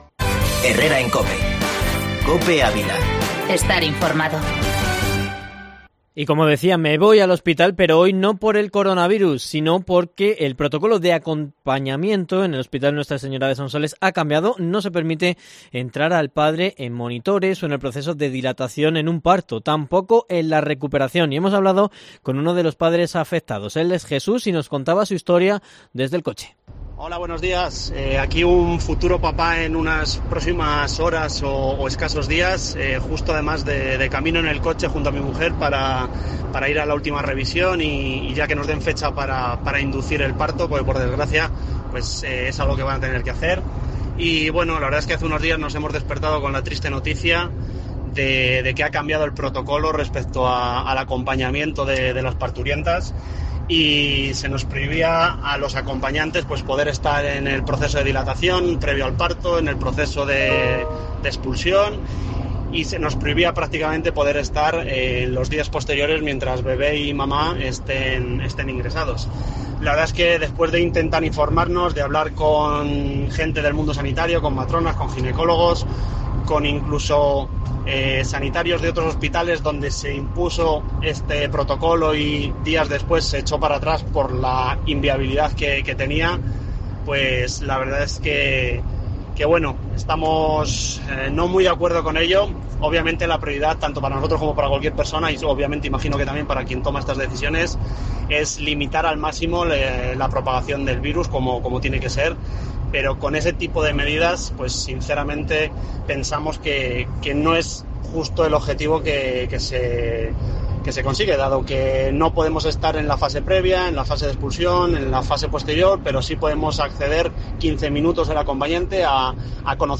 Reportaje sonoro sobre los partos en Ávila